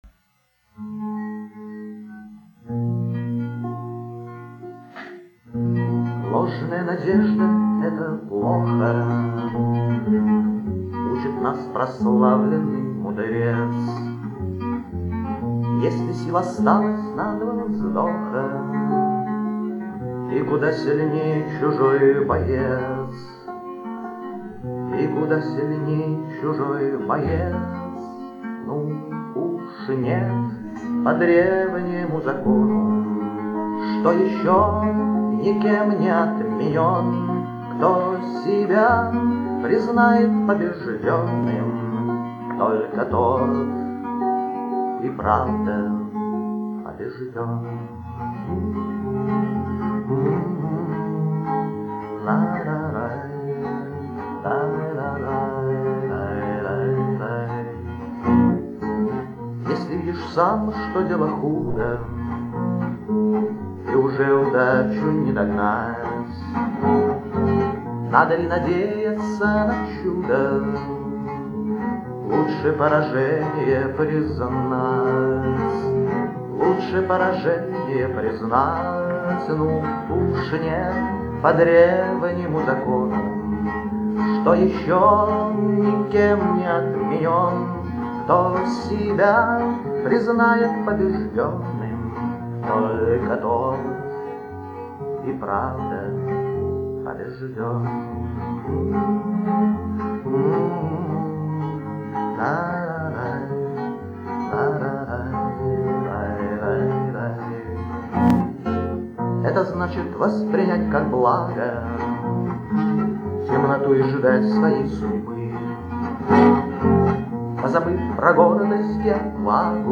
28 сентября 2001 г., Абрау-Дюрсо